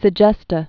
(sĭ-jĕstə, sĕ-jĕstä)